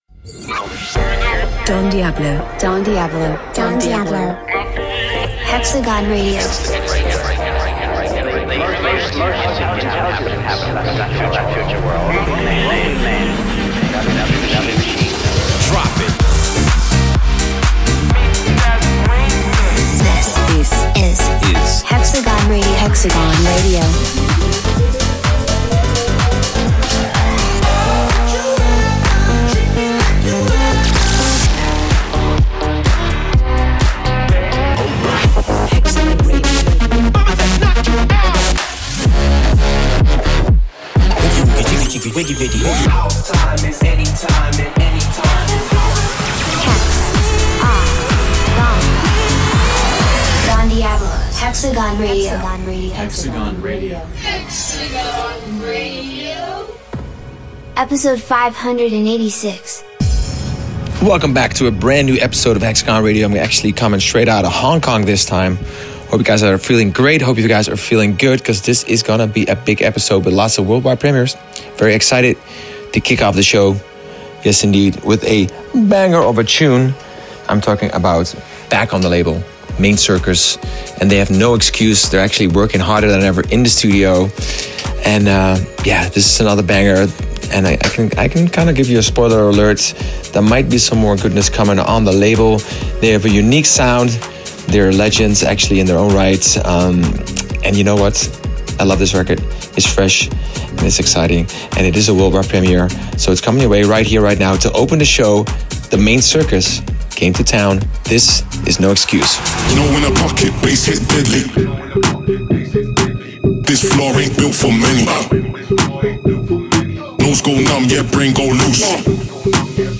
music DJ Mix in MP3 format
Genre: Bass House